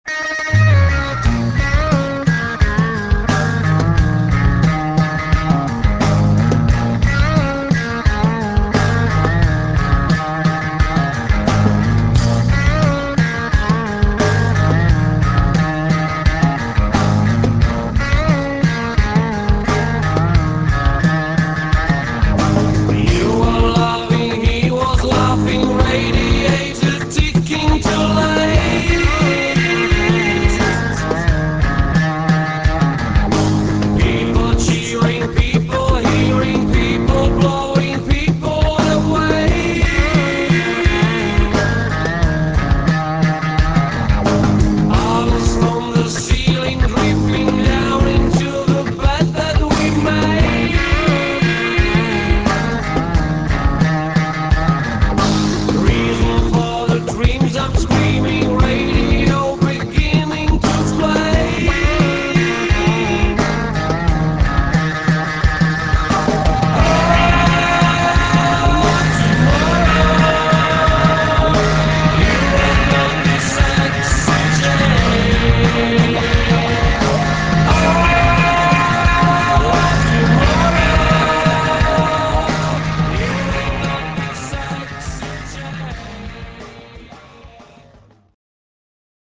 176 kB MONO